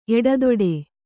♪ eḍadoḍe